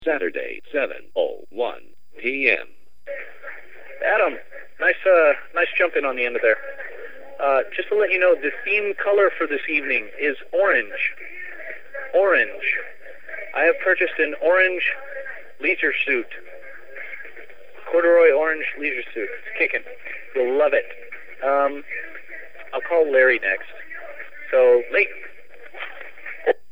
Playing all kinds of grooves and having a crazy time.